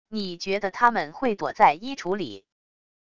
你觉得他们会躲在衣橱里wav音频生成系统WAV Audio Player